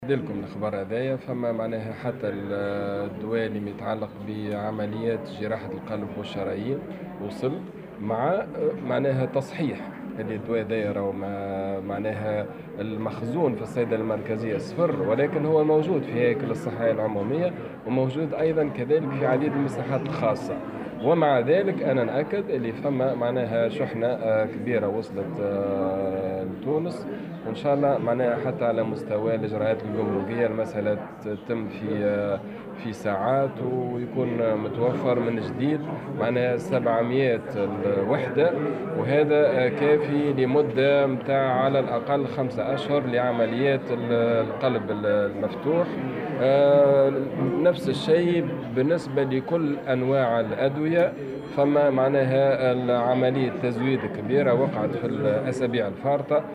وأضاف الوزير في تصريح اليوم لمراسل "الجوهرة أف أم" على هامش زيارة أداها إلى مستشفى "شارل نيكول"، أن من بين هذه الأدوية التي تم توريدها الدواء المستعمل في جراحة القلب المفتوح (700 وحدة)، مشيرا إلى أن الكمية المستوردة تكفي لمدّة 5 أشهر على الأقل، وفق قوله.